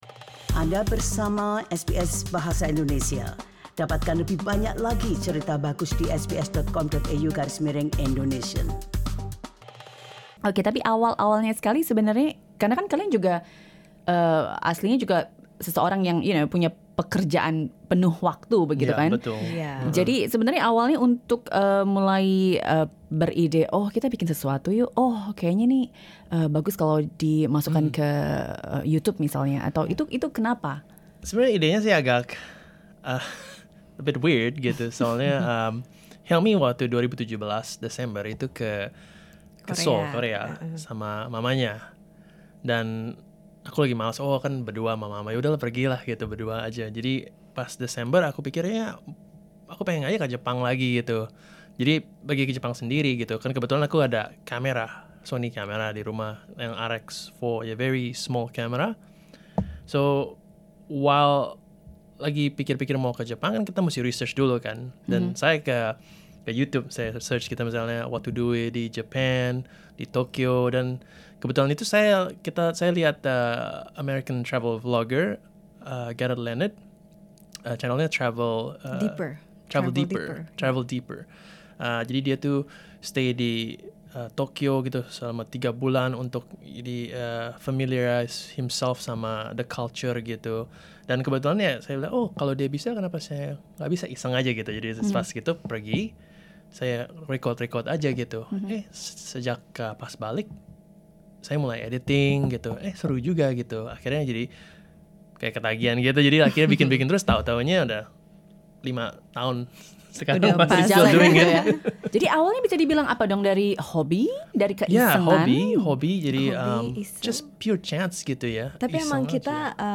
Listen to the full interview: LISTEN TO From bank employee to video creator